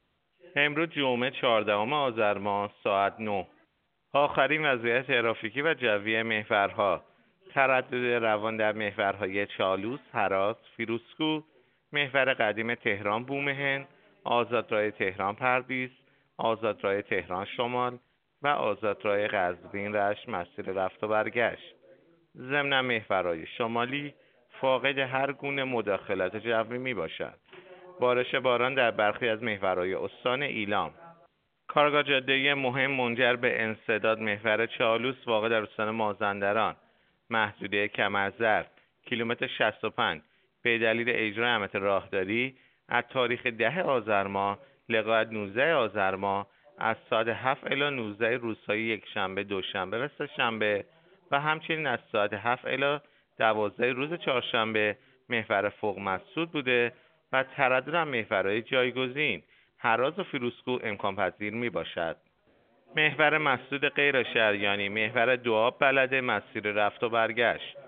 گزارش رادیو اینترنتی از آخرین وضعیت ترافیکی جاده‌ها ساعت ۹ چهاردهم آذر؛